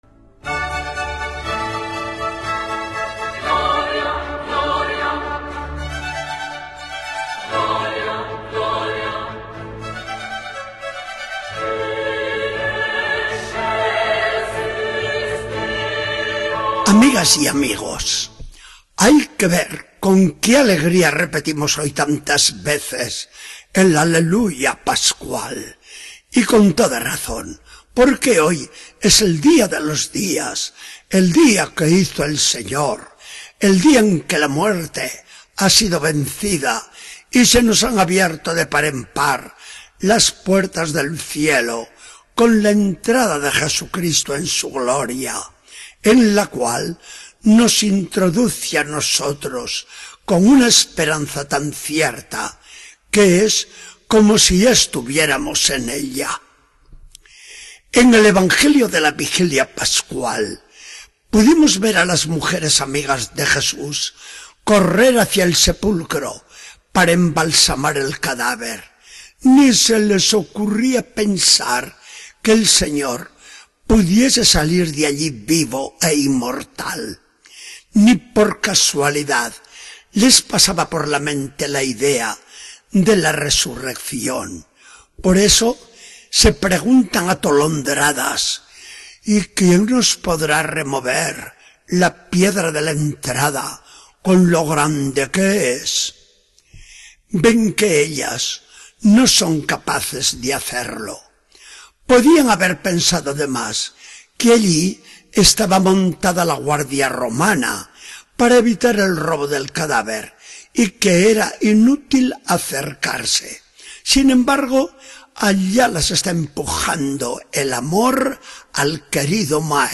Charla del día 20 de abril de 2014. Del Evangelio según San Juan 20, 1-9.